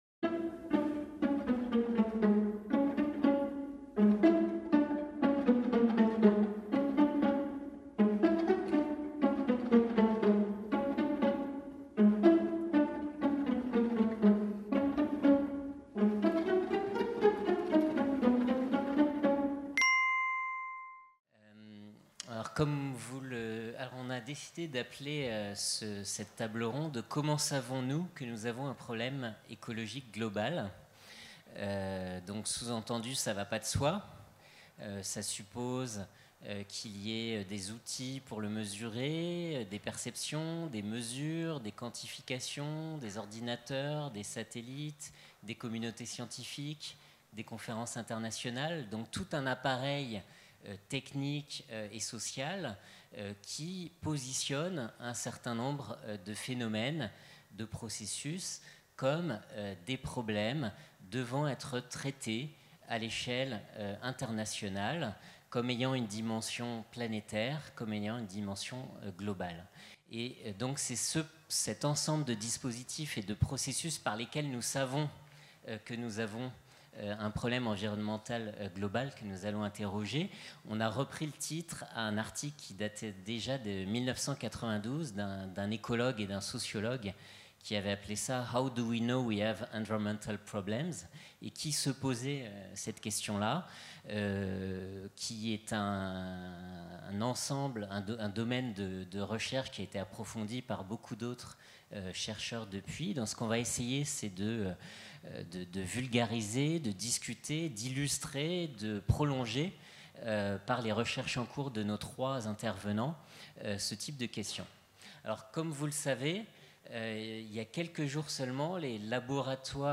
Table ronde - Comment savons-nous que nous avons un problème environnemental global ?